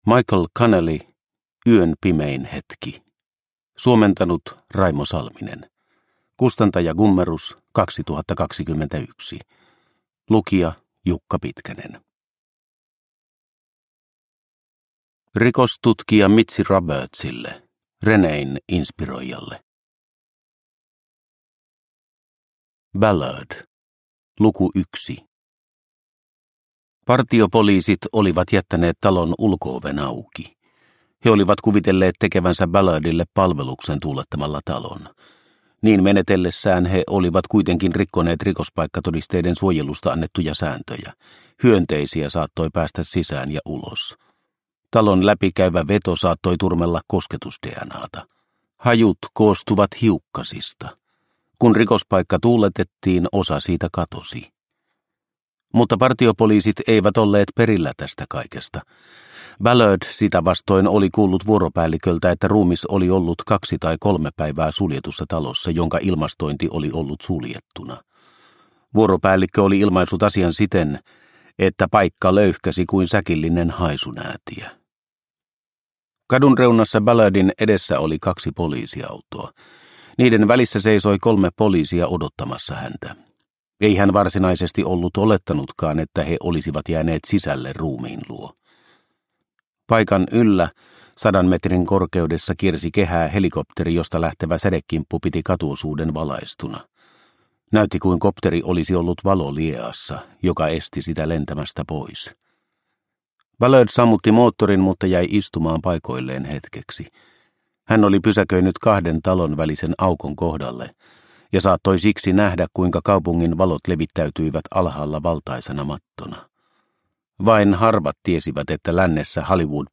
Yön pimein hetki – Ljudbok